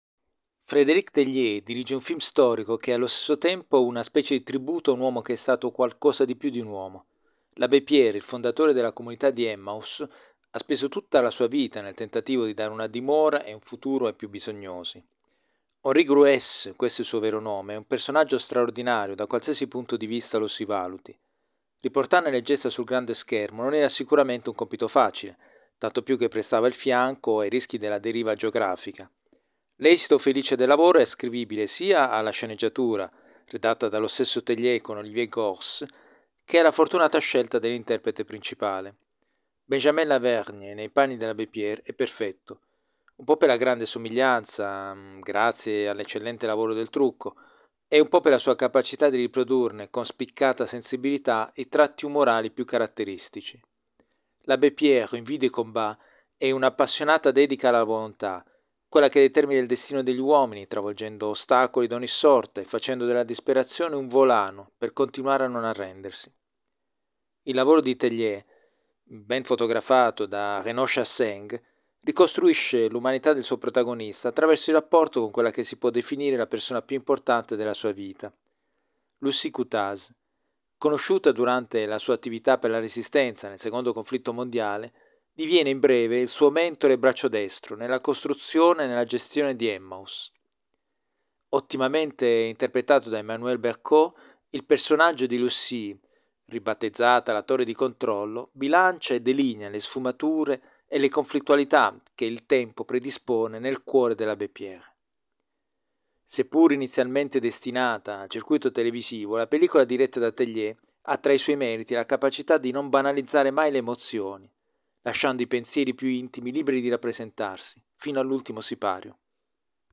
In un brevce incontro con la stampa, in occasione della proiezione del film nella ventesima edizione del Biografilm, lo stesso Lavernhe ha sottolineato il suo rapporto con il suo personaggio e l’influenza che ha avuto su di lui: